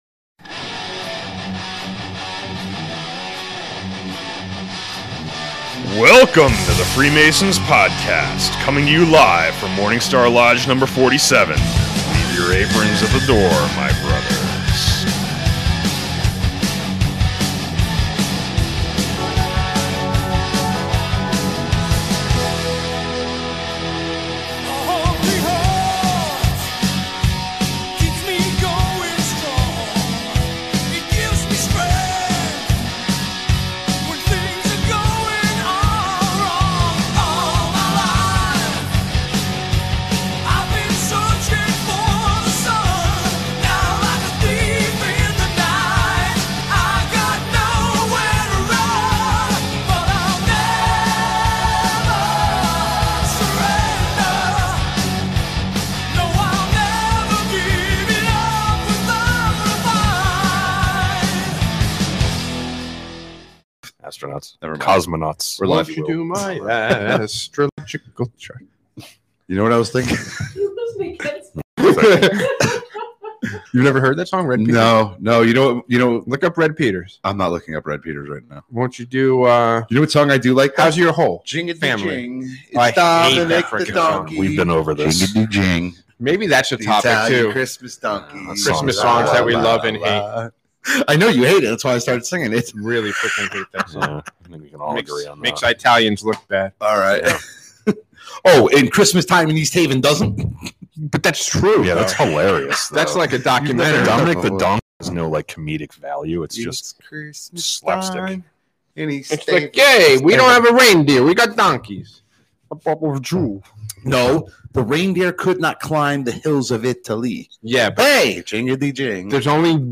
In this episode the boys discuss what a Cowan and eavesdropper is and tell stories of Lodge creepers, and recap with security to keep a Lodge safe.... sorry audio went bat shit...